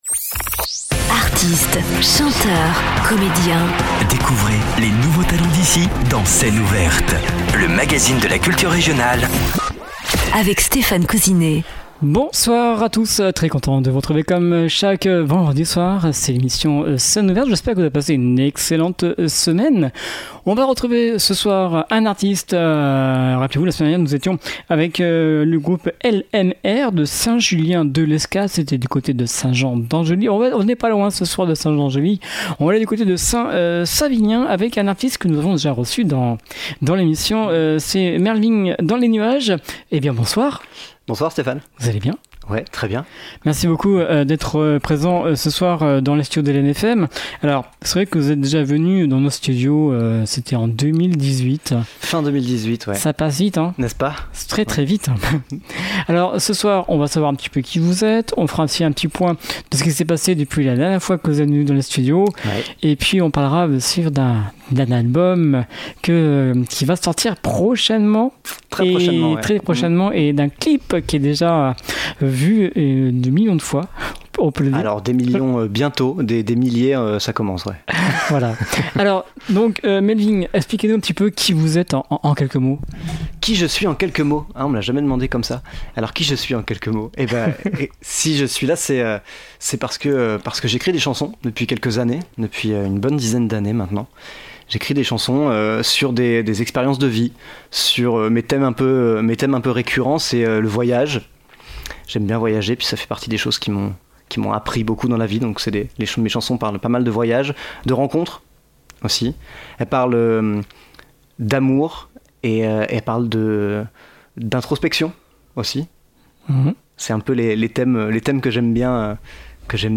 Le groove à la guitare et l’envie de vivre au cœur